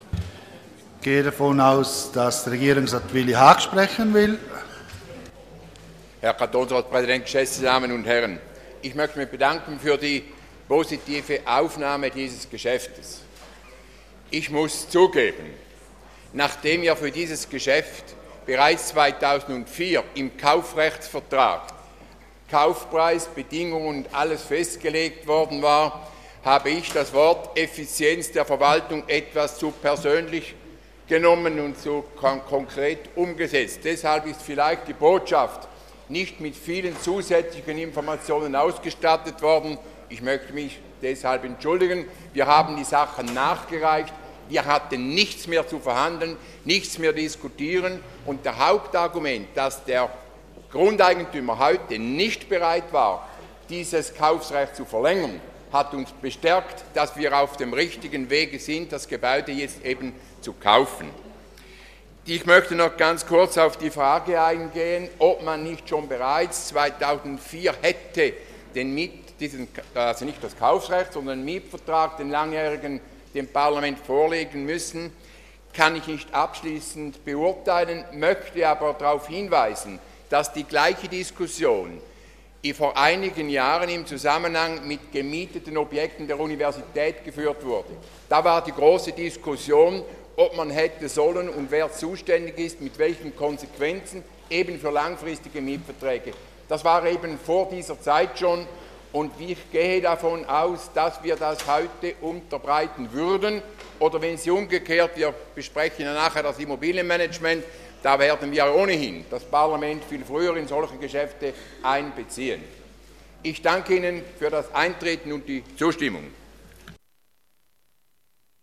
Session des Kantonsrates vom 2. bis 4. Juni 2014